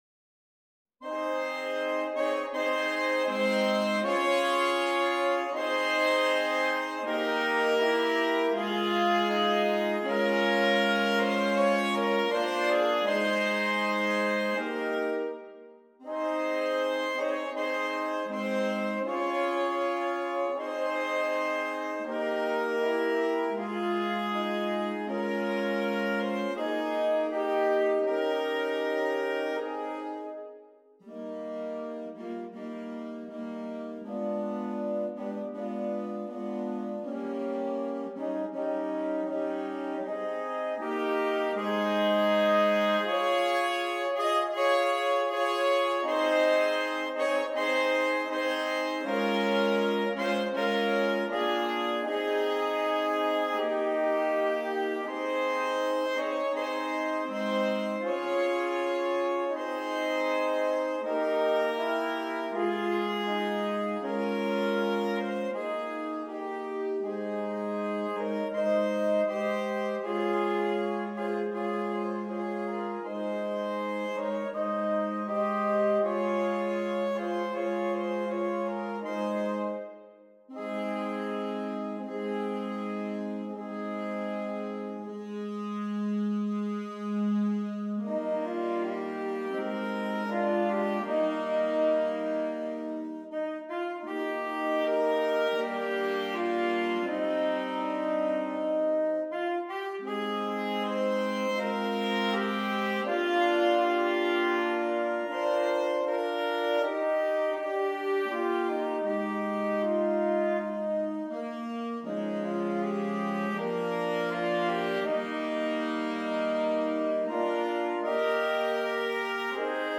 4 Alto Saxophones